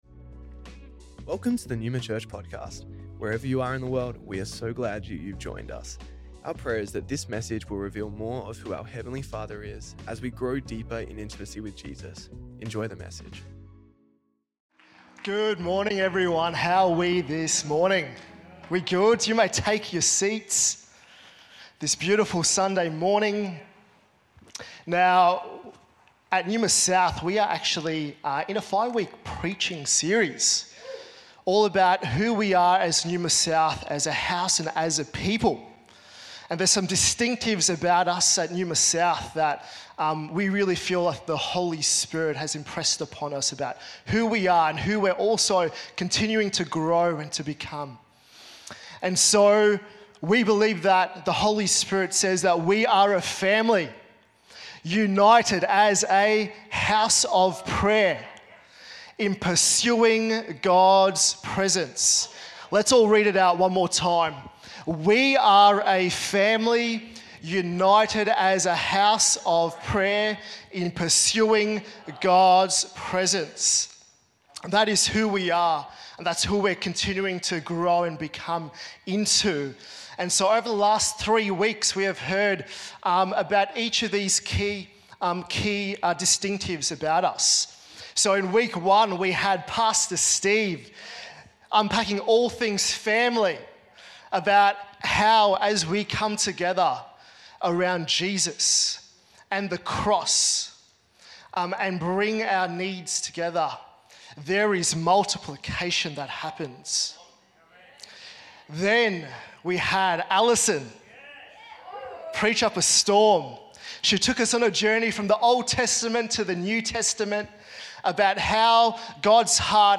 Neuma Church Melbourne South Originally Recorded at the 10AM Service on Sunday 23rd Mark 2025.